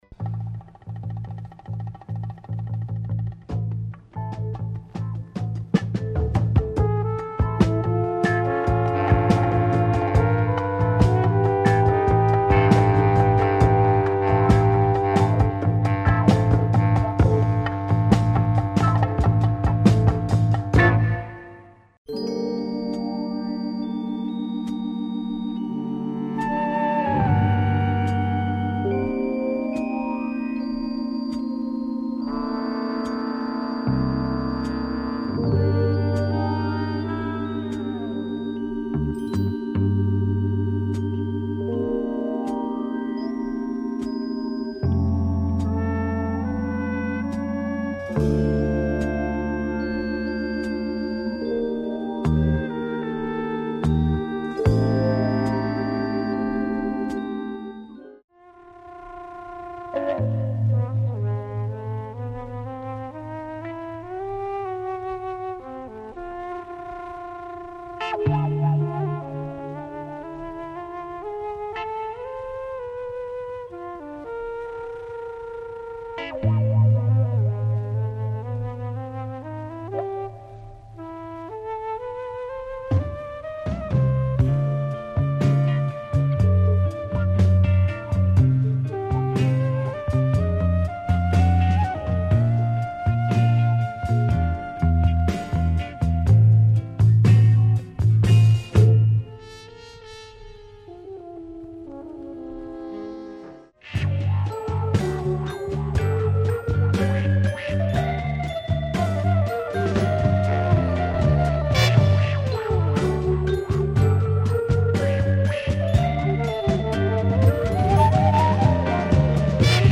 Pop, jazz and groove
funky chase blaxploitation tune with percussion breaks.